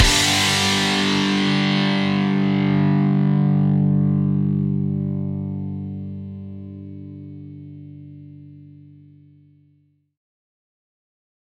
标签： 168 bpm Punk Loops Guitar Electric Loops 1.94 MB wav Key : D Pro Tools
声道立体声